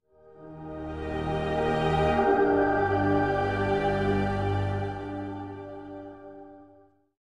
Category:Fallout 3 endgame narrations Du kannst diese Datei nicht überschreiben.